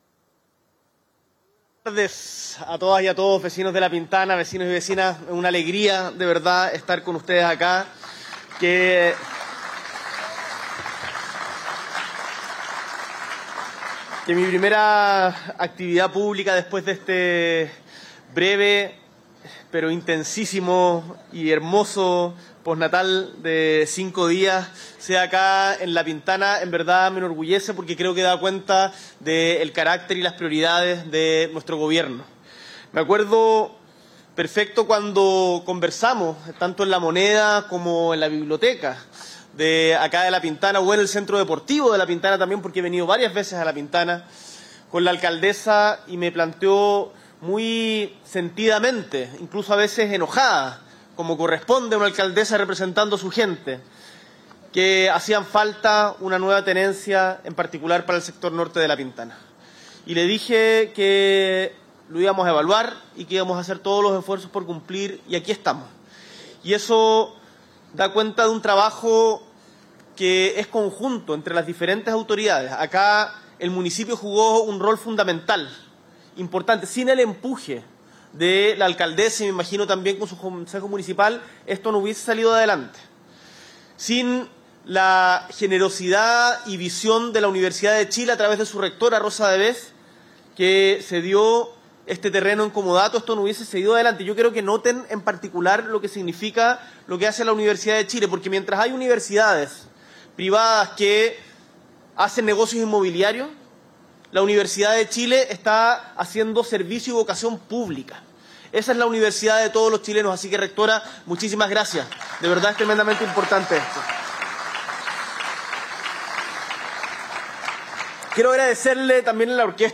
S.E. el Presidente de la República, Gabriel Boric Font, encabeza la inauguración de la nueva tenencia de Carabineros de Chile Suboficial Mayor Óscar Galindo Saravia